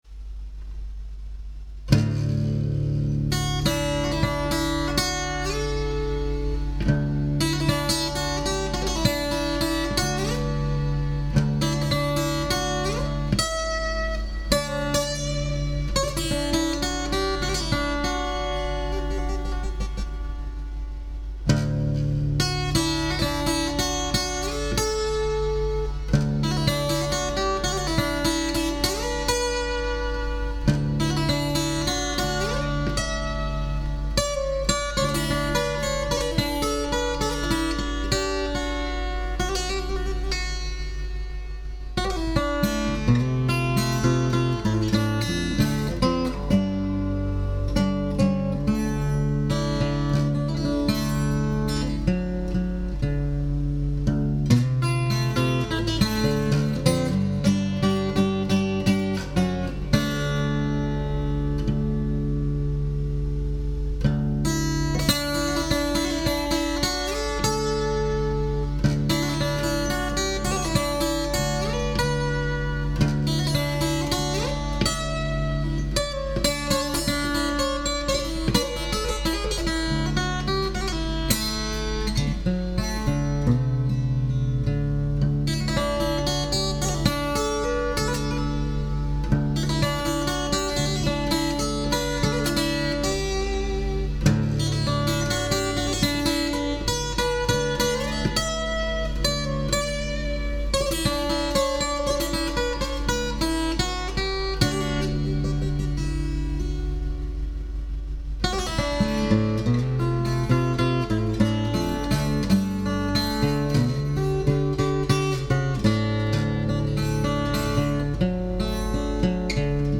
The instrumental I call ‘Faintly Fahey’ started as a sort of fake Irish air, then got translated into a bottleneck version. This is a non-slide version.